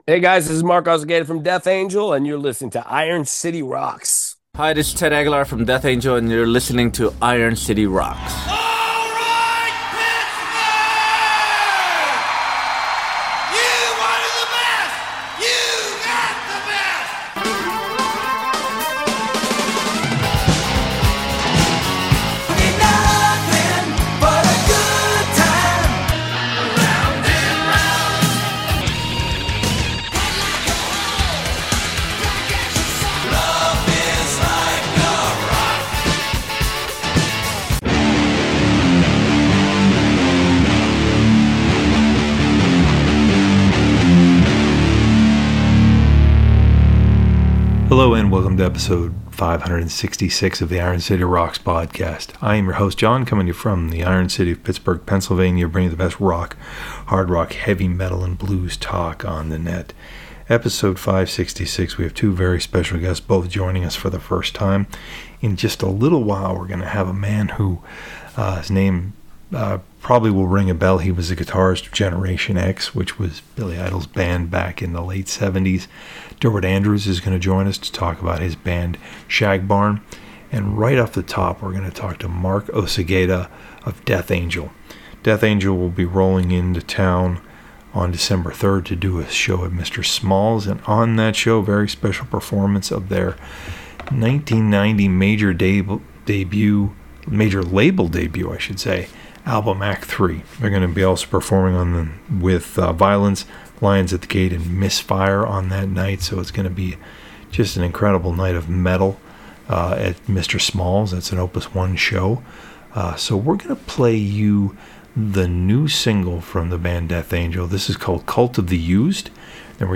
In Episode 566 of the Iron City Rocks podcast, we welcome Death Angel’s Mark Osegueda.